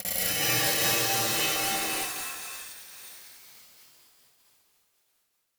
Upright piano_Fx_Atmospheric element_Surreal-005.wav